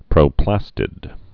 (prō-plăstĭd)